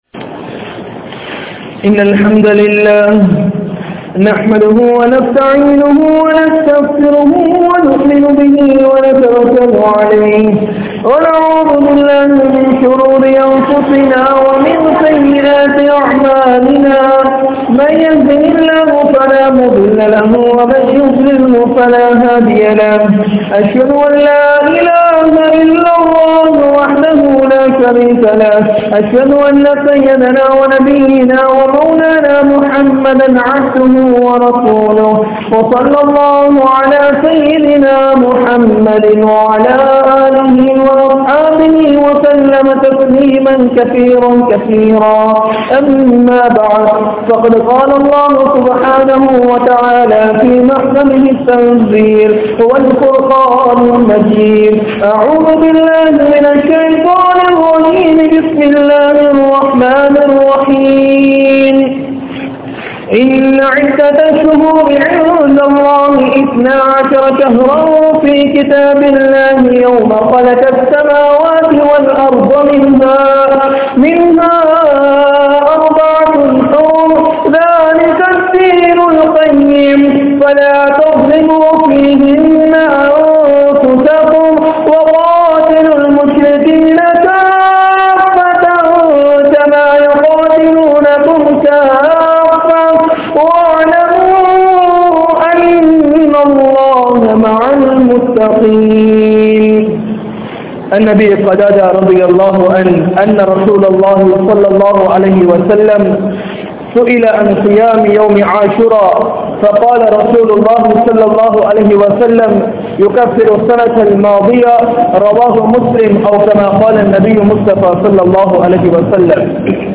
Muharram Maatham Koorum Padippinai (முஹர்ரம் மாதம் கூறும் படிப்பினை) | Audio Bayans | All Ceylon Muslim Youth Community | Addalaichenai
Kurunegala, Mallawapitiya Jumua Masjidh